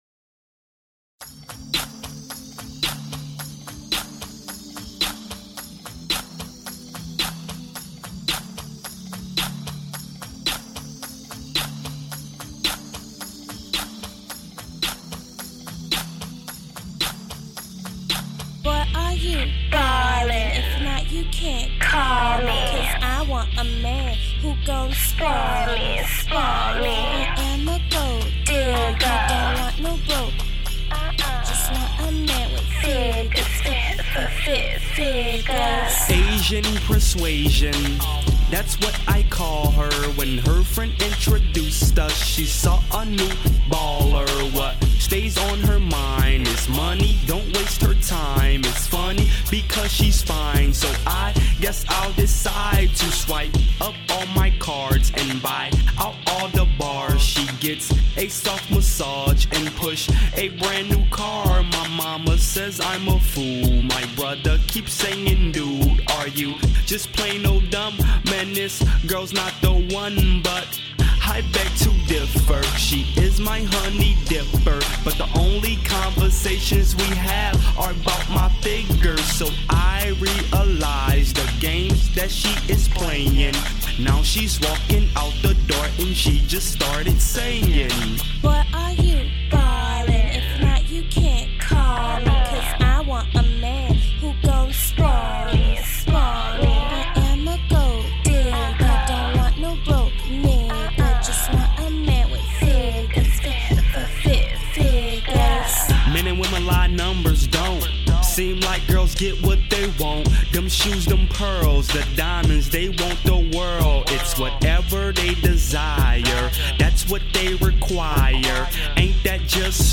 Created 2024-03-12 01:37:57 Hip hop 0 ratings